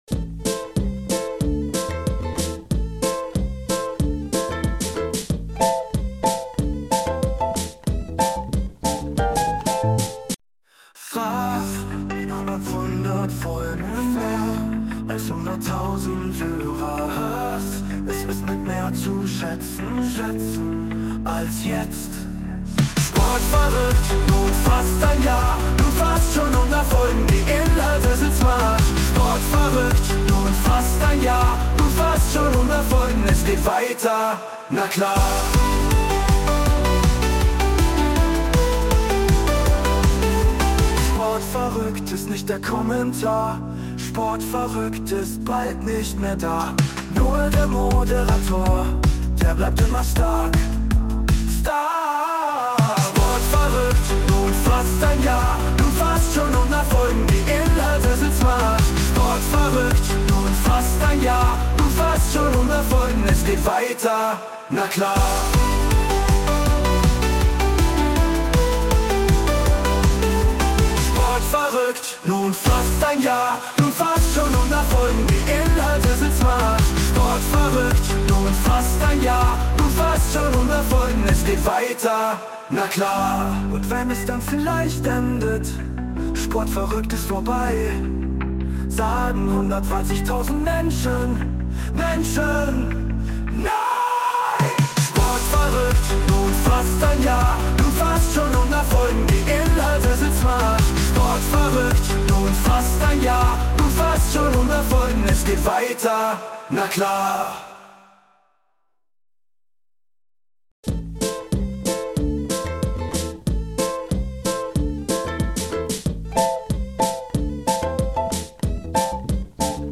Anlässlich dazu heute ein Song, um zu sagen: DANKE!!!